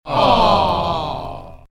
S-AWW-GROUP-A.mp3